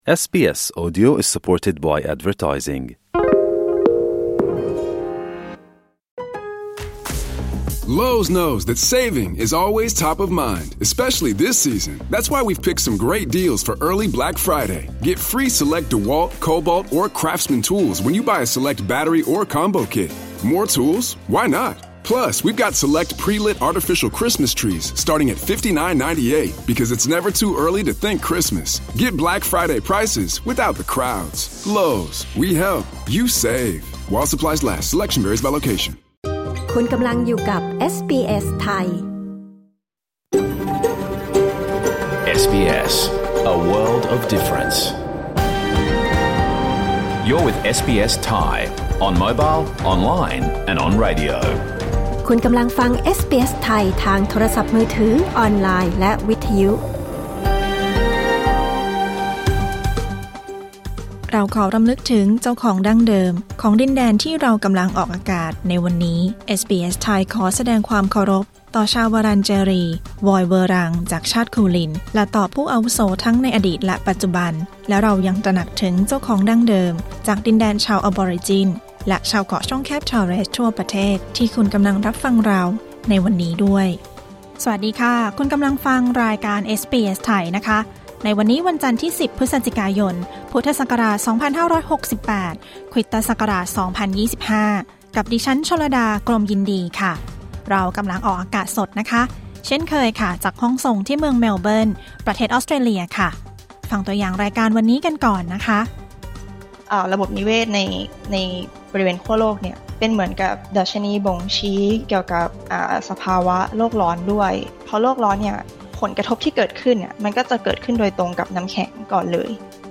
รายการสด 10 พฤศจิกายน
สรุปข่าวรอบวัน | การเหยียดเชื้อชาติในที่ทำงานที่ยังกระทบผู้อพยพและชนพื้นเมือง | Australia Explained: คำแนะนำเรื่องการหาคู่ในออสเตรเลีย